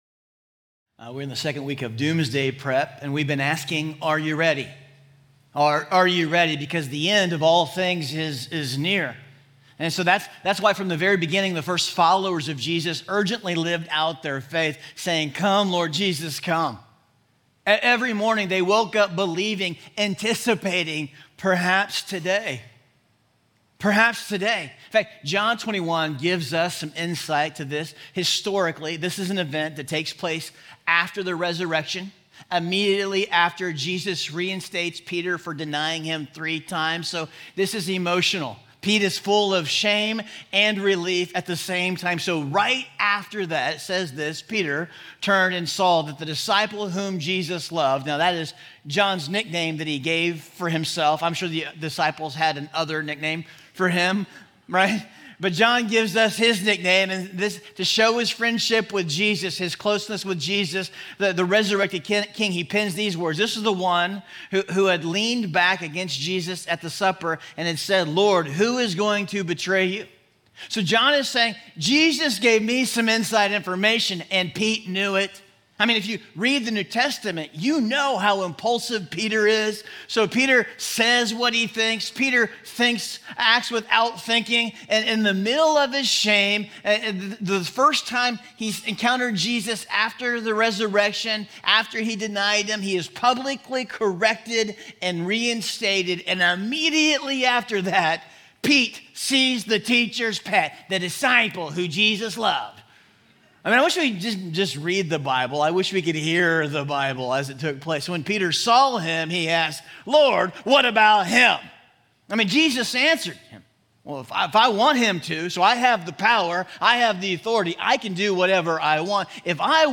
Doomsday_Prep_Week_02_Message_Audio.mp3